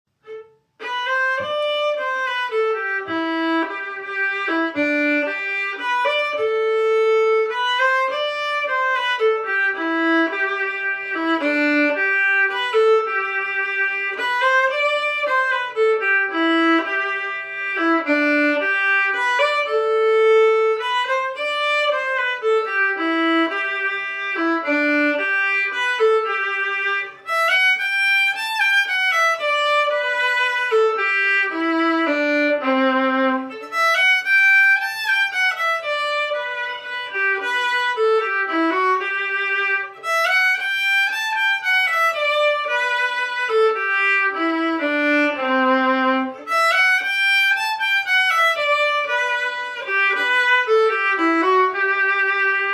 Key: G
Form: Waltz
Genre/Style: Waltz